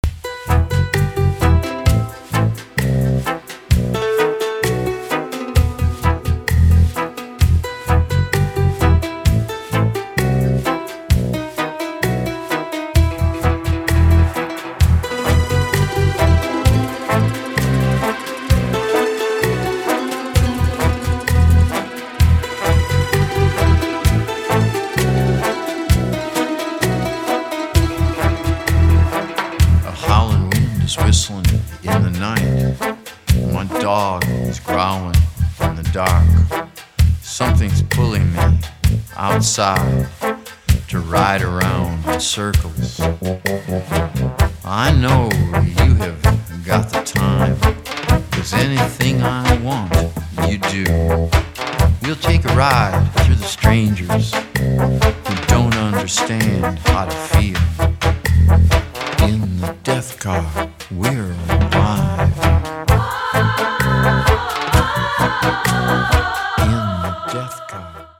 • Качество: 320, Stereo
фолк
рок
трамбон